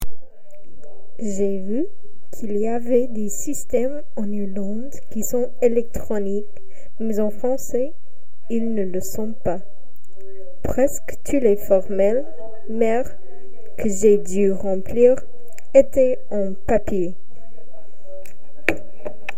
Cabine de témoignages
Témoignage du 24 novembre 2025 à 19h26